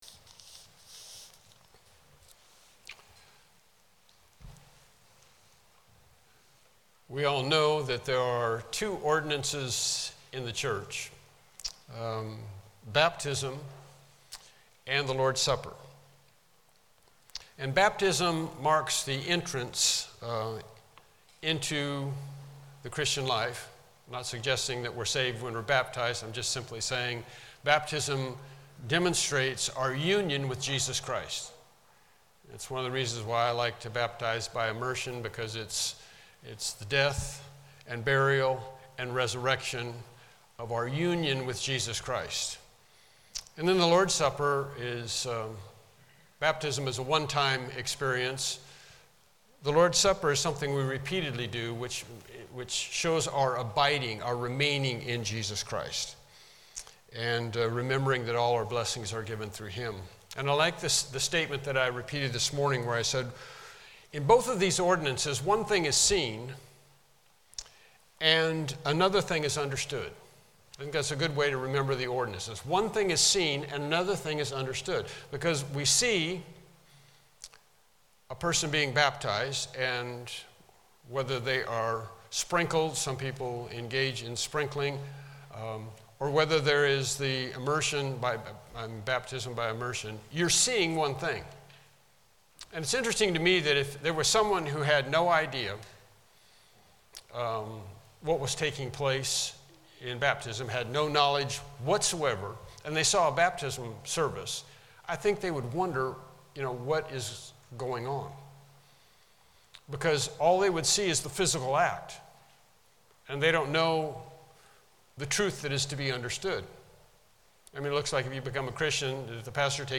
Evening Sermons Passage: Psalms 113-118 Service Type: Evening Worship Service